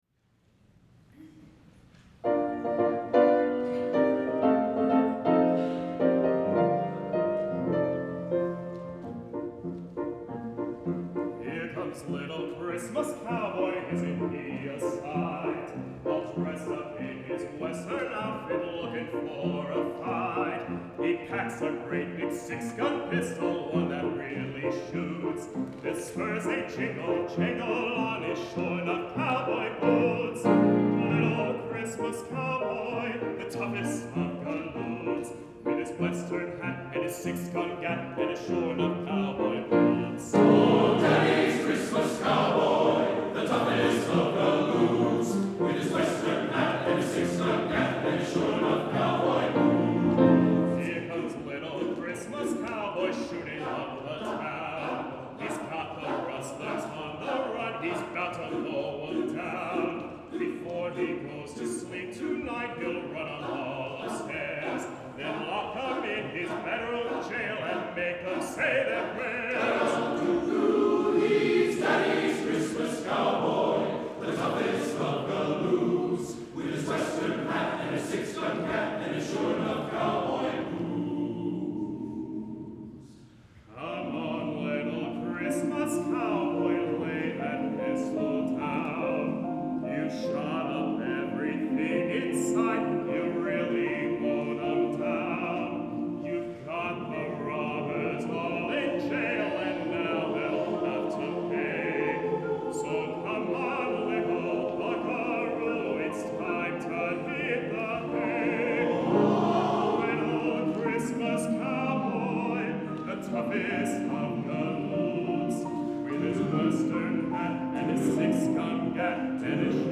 Vocal Samples